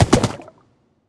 Minecraft Version Minecraft Version snapshot Latest Release | Latest Snapshot snapshot / assets / minecraft / sounds / mob / horse / skeleton / water / gallop3.ogg Compare With Compare With Latest Release | Latest Snapshot
gallop3.ogg